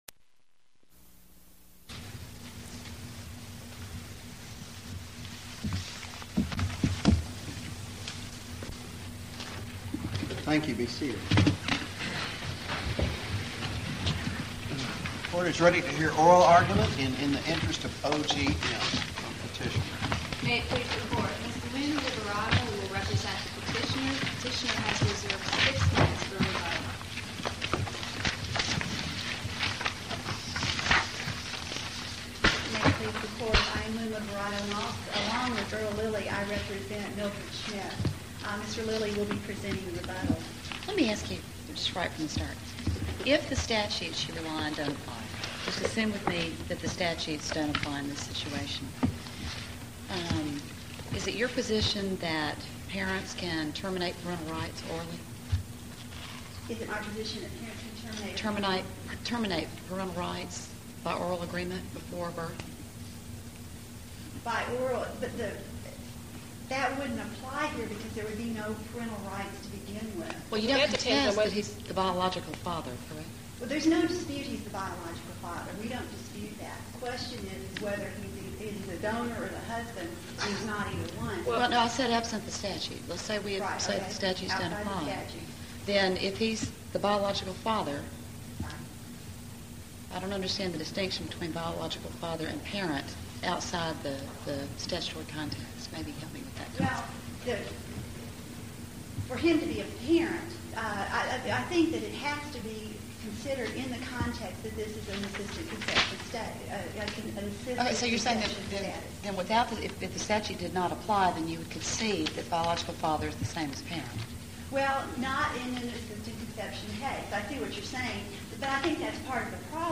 Case No. 99-0026 Oral Arguments Audio (MP3)